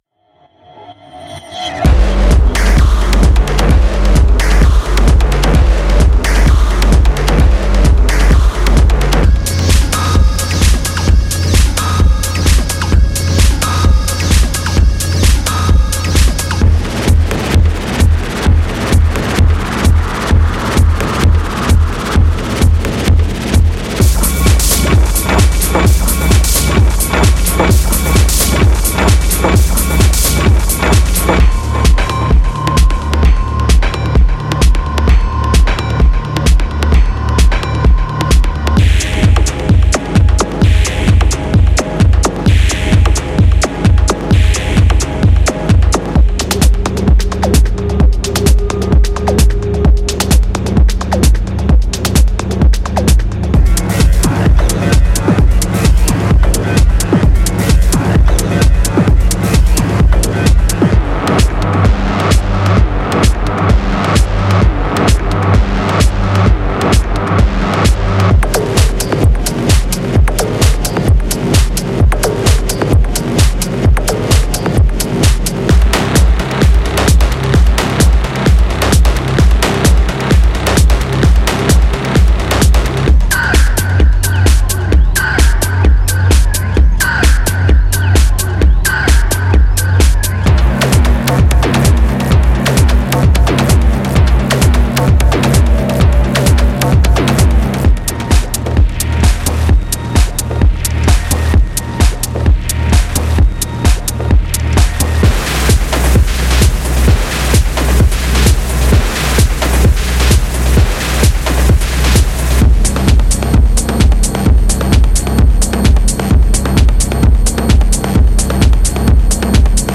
•80 Modular Loops
•30 Texture Loops
•25 Synth Loops
•40 Drum Loops